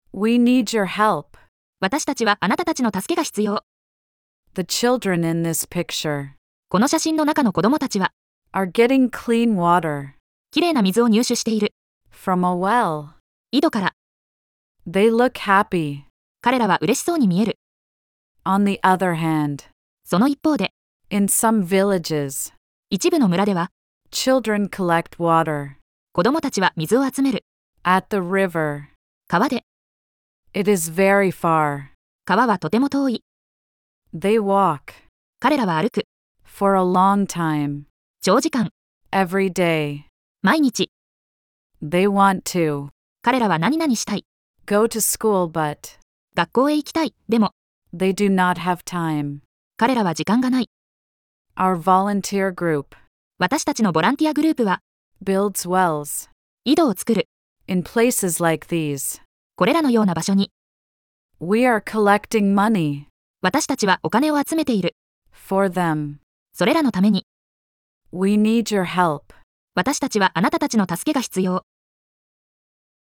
♪ 習得用の音声(英⇒日を区切りごと)：